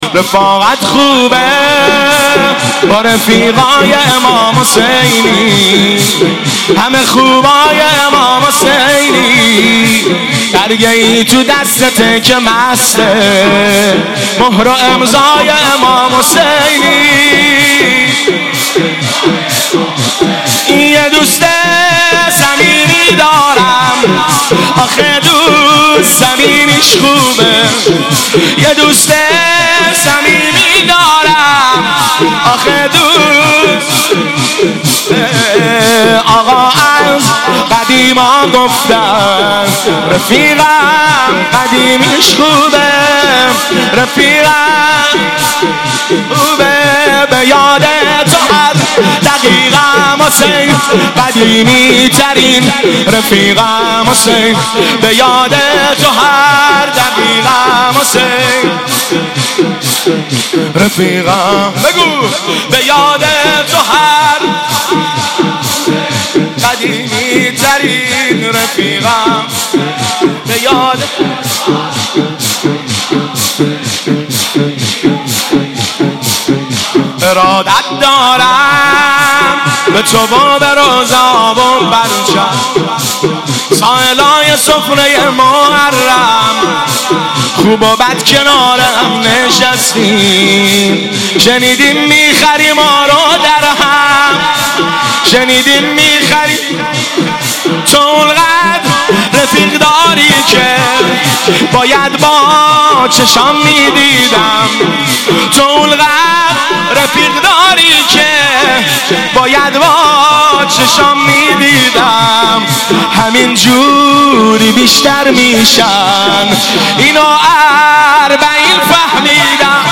دانلود مداحی و روضه خوانی شب پنجم ماه محرم در سال 1396
بخش دوم – شور